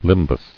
[lim·bus]